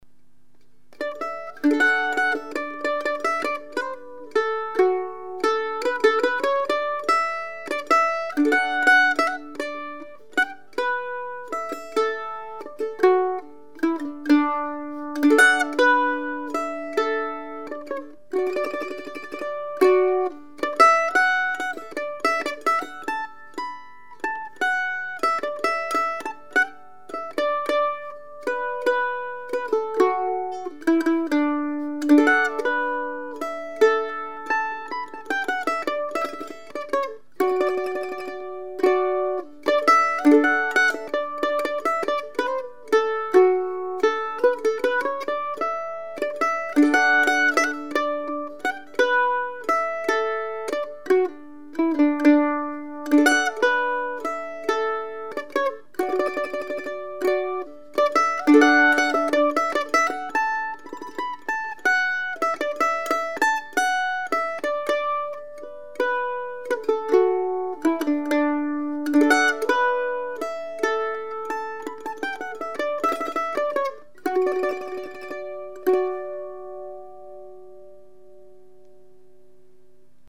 2 Point A5 Mandolin #342  $5200 (includes case)
Sweet, responsive and very evenly balanced, and it does like the Thomastic strings.  Beautiful sound when played soft, but can really push out the volume when hit hard.